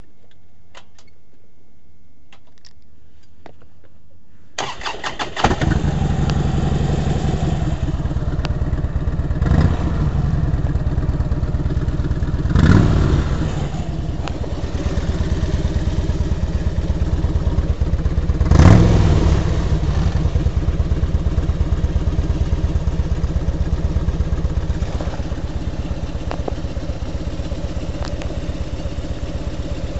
交通工具 " 哈雷戴维森
描述：一辆哈雷戴维森摩托车在勒阿弗尔港从我身边经过。Zoom H4录音机。
Tag: 发动机 戴维森 现场记录 摩托车 轰鸣 哈雷